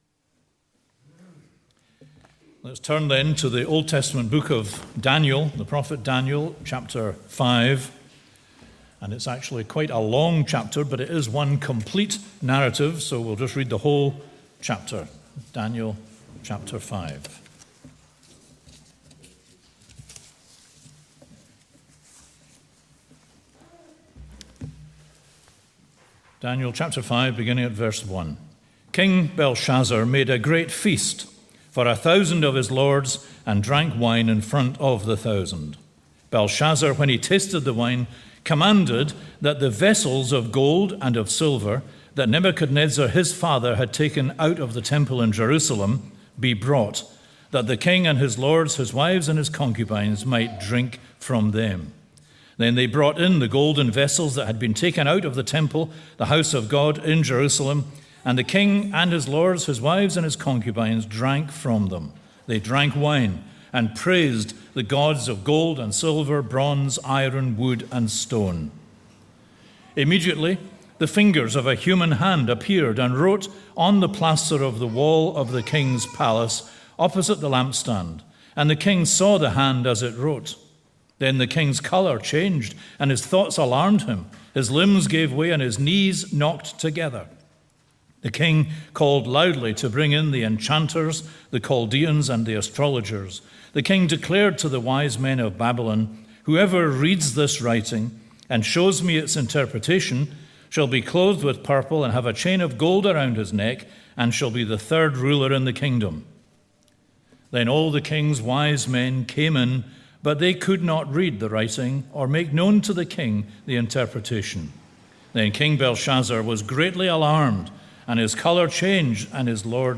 The Writing is on the Wall | SermonAudio Broadcaster is Live View the Live Stream Share this sermon Disabled by adblocker Copy URL Copied!